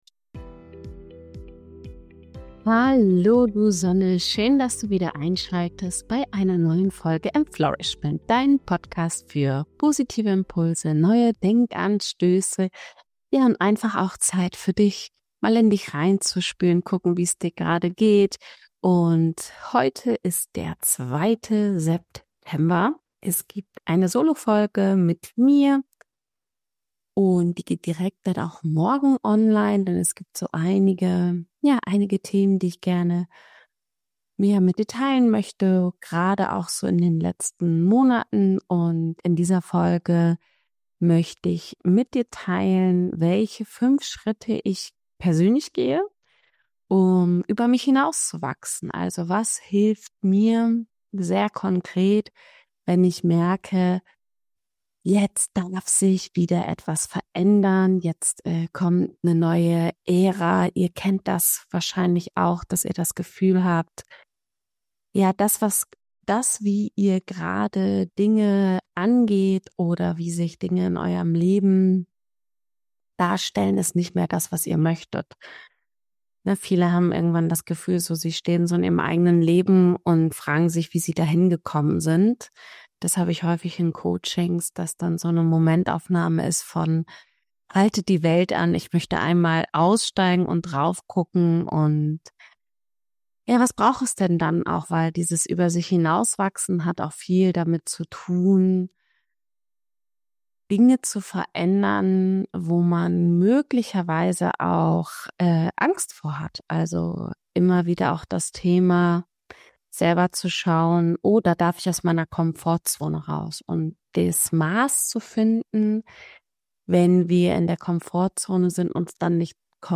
In dieser kraftvollen Solofolge teile ich meine persönliche Reise – und vor allem fünf entscheidende Schritte –, mit denen ich immer wieder über mich hinauswachse, ohne mich zu überfordern.